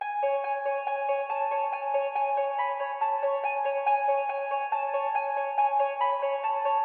钢琴3
Tag: 140 bpm Rap Loops Piano Loops 1.15 MB wav Key : G